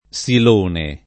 Sil1ne] pers. m. stor.